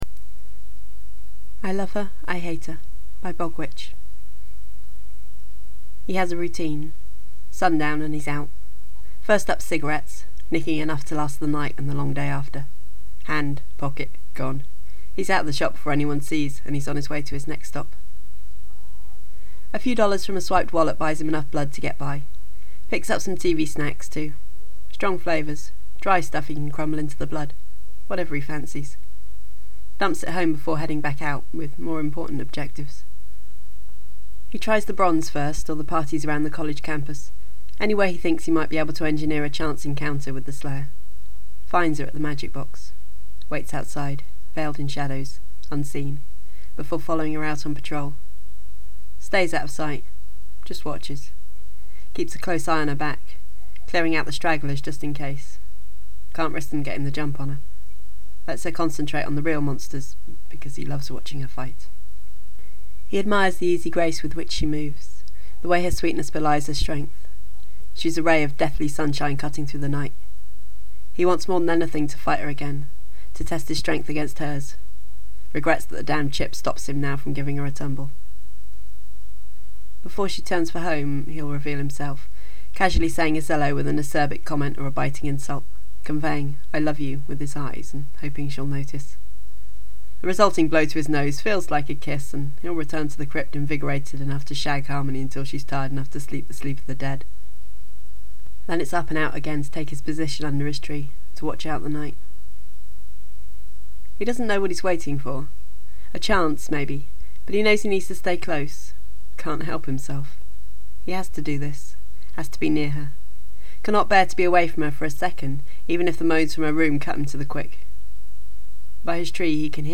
Just in case anyone doesn’t know: Podfic – FanFiction that is read aloud and then made available for download.